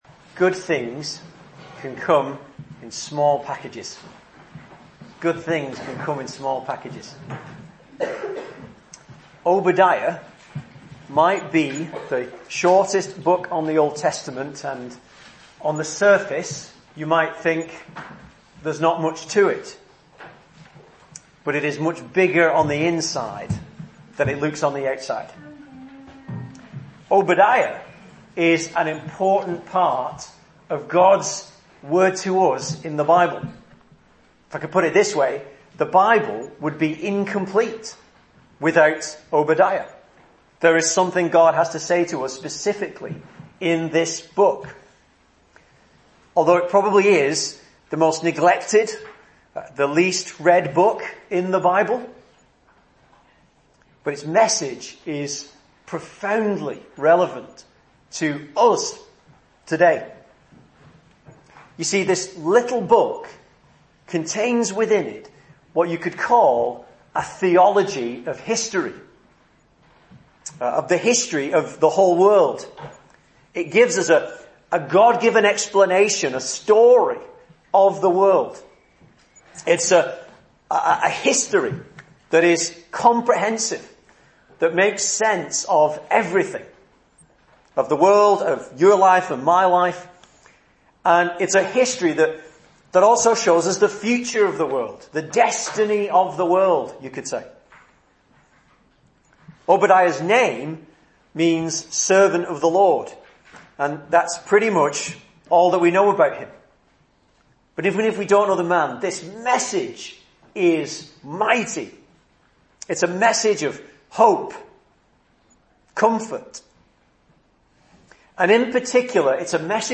The Message of Obadiah: A Great Vision of History! - Bury St Edmunds Presbyterian Church